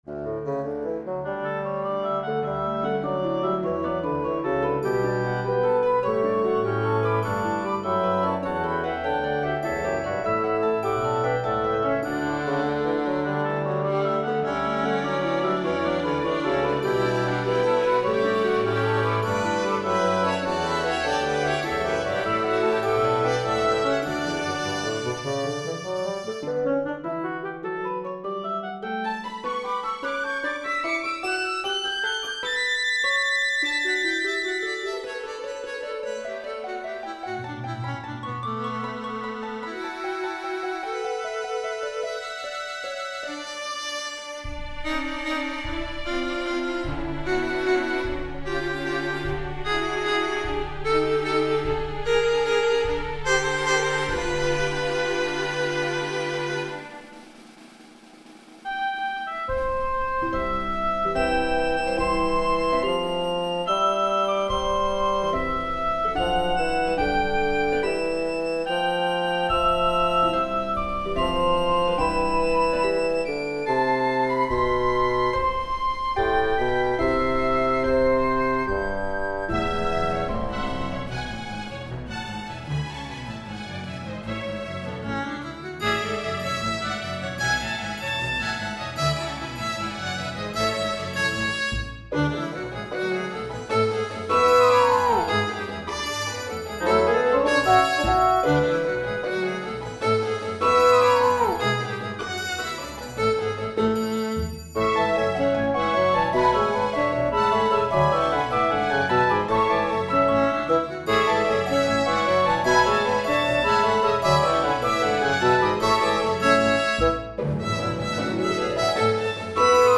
This movement is a play-by-play aural illustration of my first experience attending a Royals game with my dad. The musical sentences are an odd length (10 measures instead of 8), to convey the sporadic, excited gait of a child tugging at the hand of a parent who won’t walk fast enough. It includes many familiar reminiscences: ascending the steps and escalators that reach to the sky, squeezing past people apologetically to get to the correct seats, the echoing of our national anthem as it bounces from one concrete wall to another, the food vendors shouting and selling their wares, the crack of the bat as it hits the ball, and the cheers in response.
II. Stadium (MIDI-rendered recording)